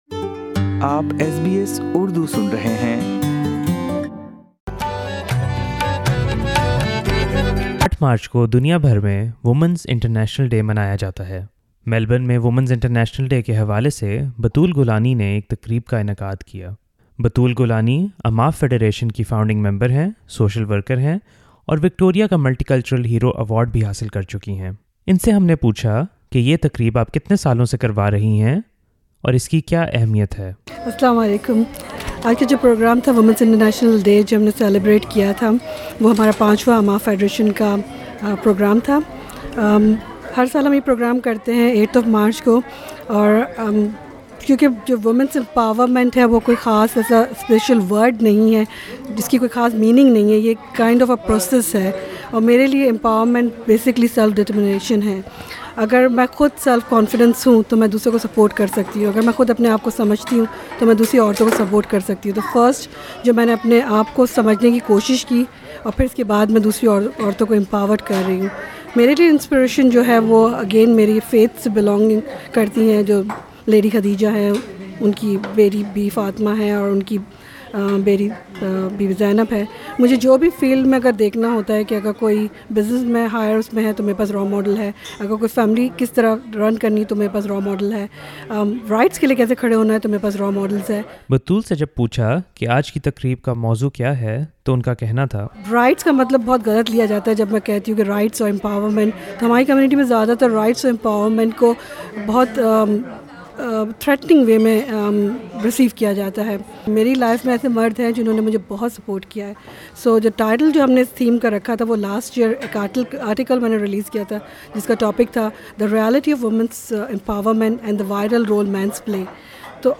Panelists share their thoughts and views at an International Women's Day discussion in Melbourne.